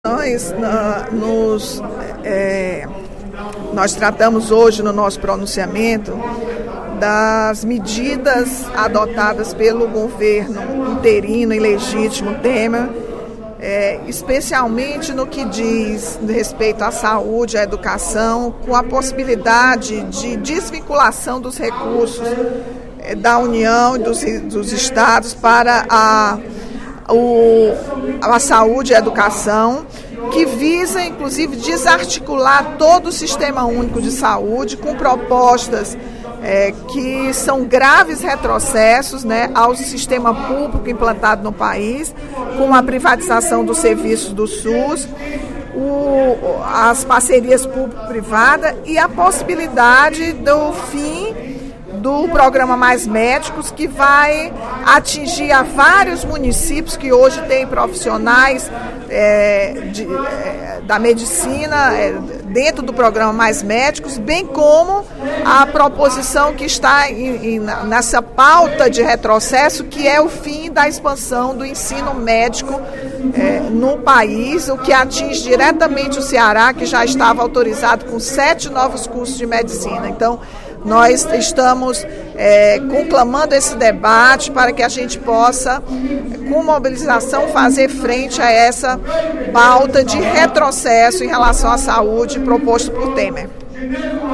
A deputada Rachel Marques (PT) criticou, no primeiro expediente da sessão plenária desta quarta-feira (25/05), as medidas tomadas pelo governo do presidente interino Michel Temer, que segundo ela, "configuram verdadeiro retrocesso para a população”.